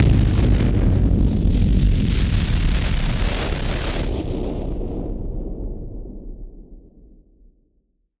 BF_SynthBomb_B-02.wav